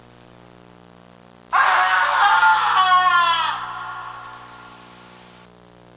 Scream Sound Effect
Download a high-quality scream sound effect.
scream-5.mp3